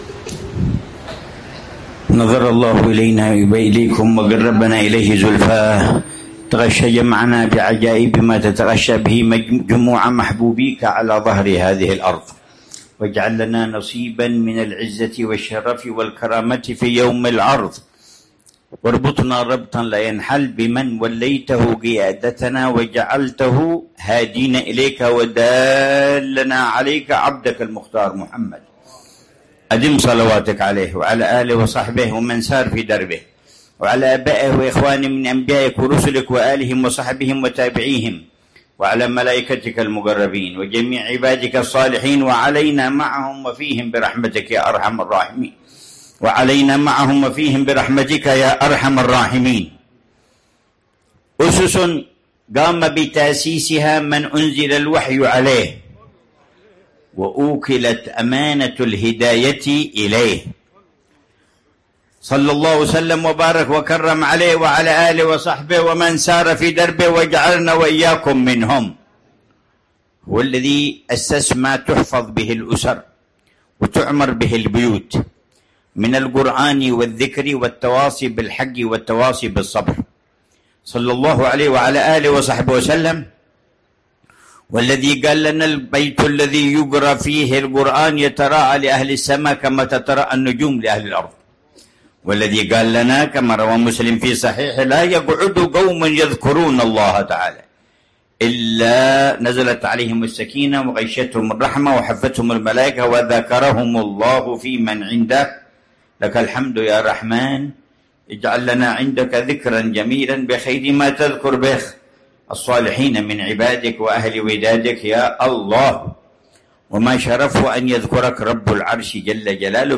كلمة الحبيب عمر بن حفيظ
كلمة العلامة الحبيب عمر بن محمد بن حفيظ